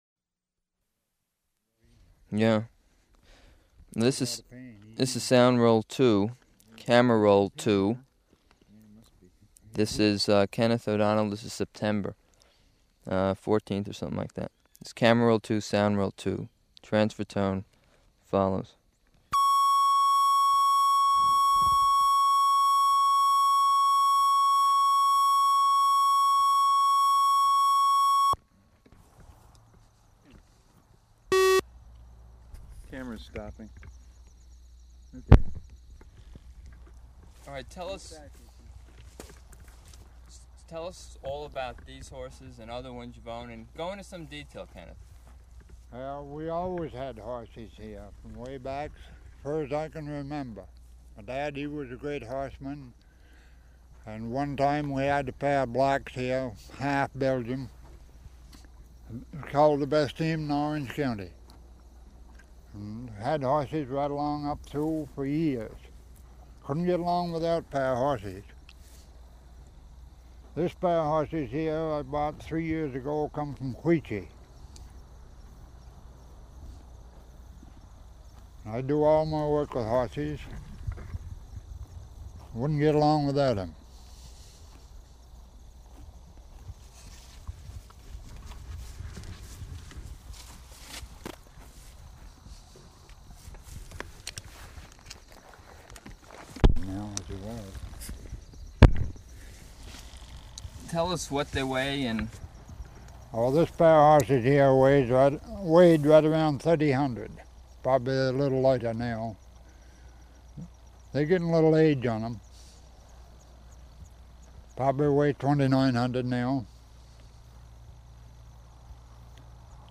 Format 1 sound tape reel (Scotch 3M 208 polyester) : analog ; 7 1/2 ips, full track, mono.